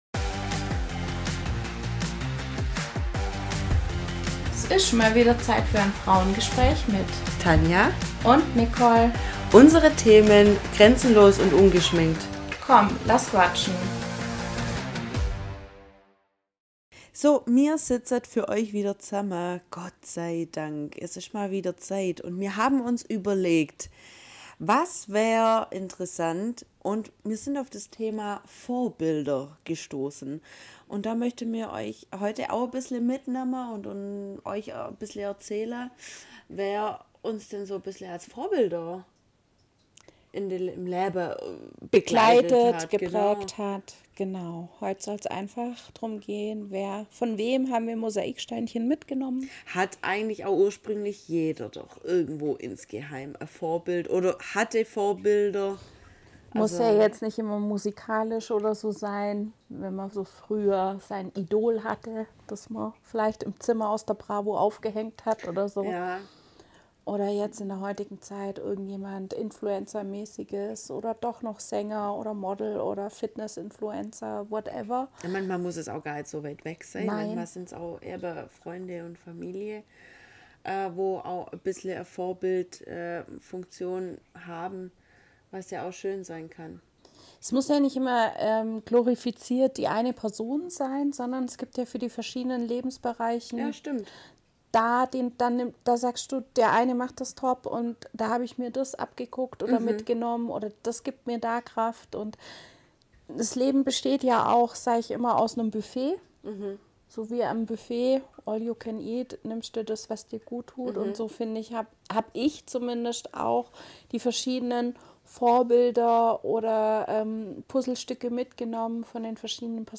#023 Unsere Vorbilder ~ Frauengespräche │ grenzenlos & ungeschminkt Podcast